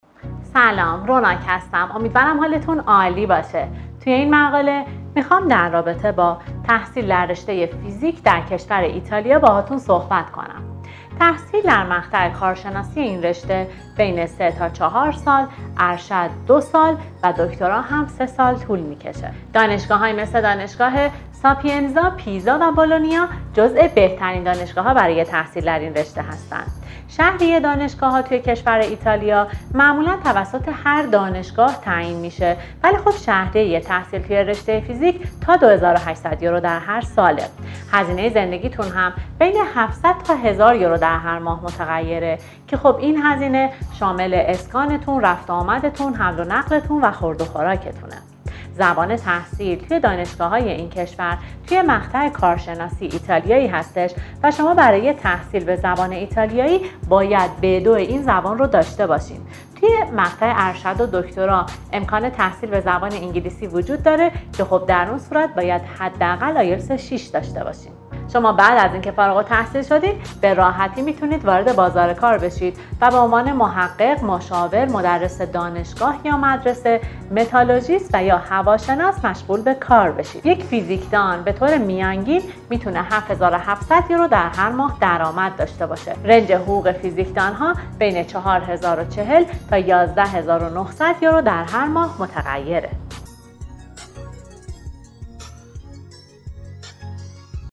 در این ویدیو همکار ما تحصیل رشته فیزیک در ایتالیا را برای شما شرح داده و می توانید با دانلود فایل پادکست در هر زمان و مکان به آن دسترسی داشته باشید.